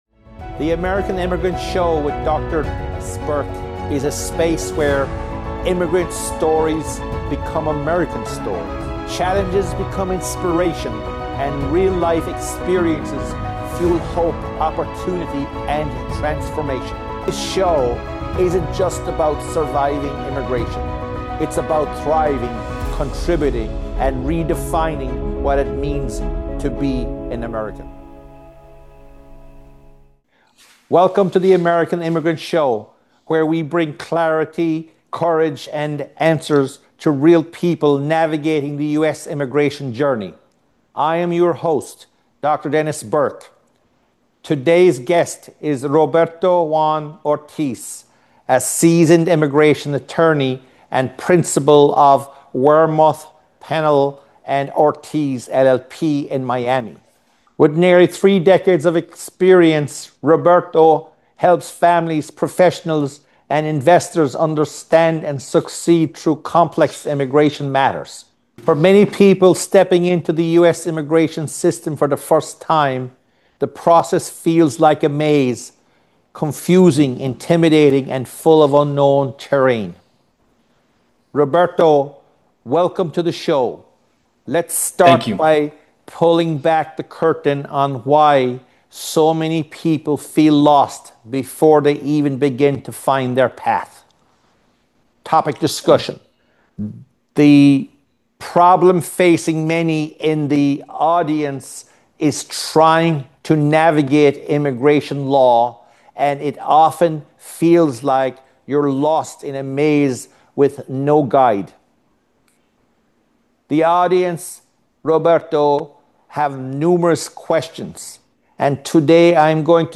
immigration attorney